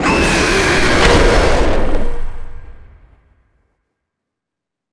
bigger_jumpscare3.wav